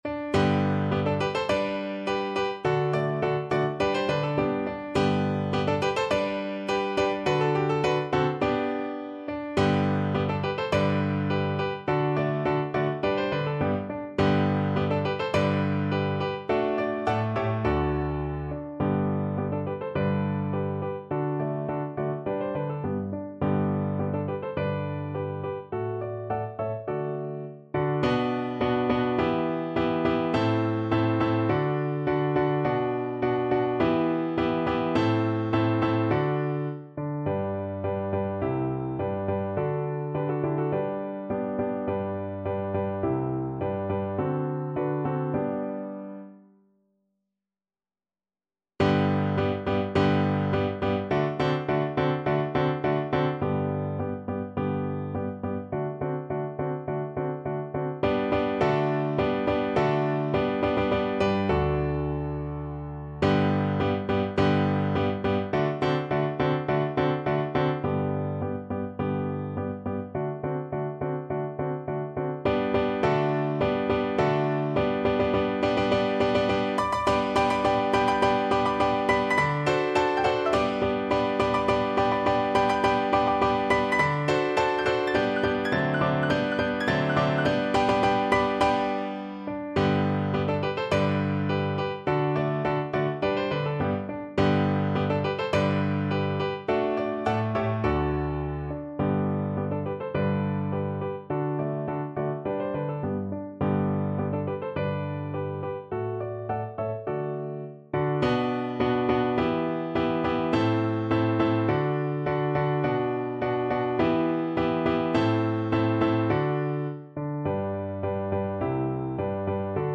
2/4 (View more 2/4 Music)
~ = 100 Molto vivace =104
Classical (View more Classical Violin Music)